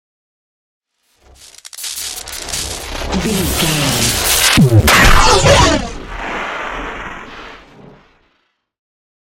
Whoosh to hit electricity large 808
Sound Effects
dark
futuristic
intense
tension
woosh to hit